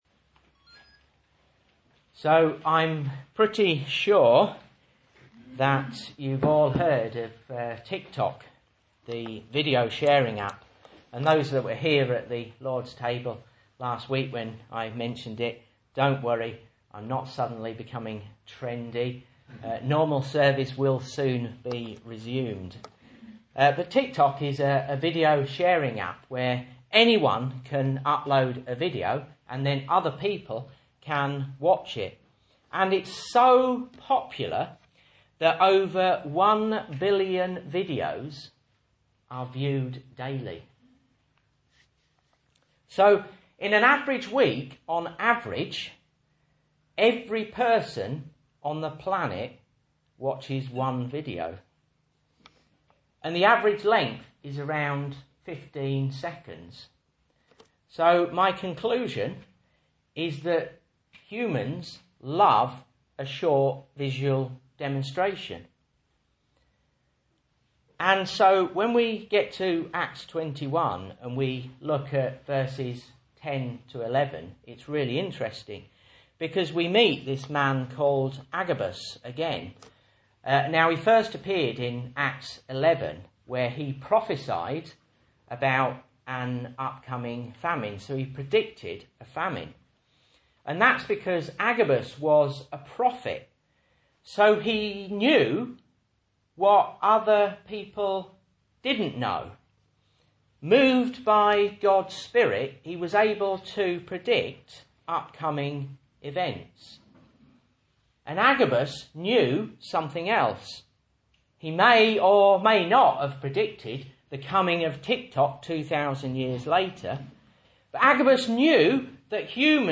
Message Scripture: Acts 21:17-29 | Listen